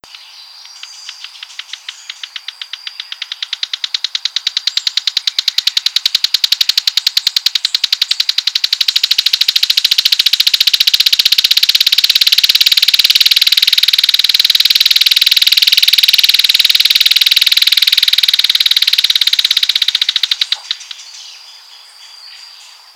Vuelos cortos y se mantienen en contacto por silbidos pausados y fuertes.
Tiene un canto que es una sucesión de silbos algo espaciados, con ligera frecuencia descendente o ascendente.
tarefero.wav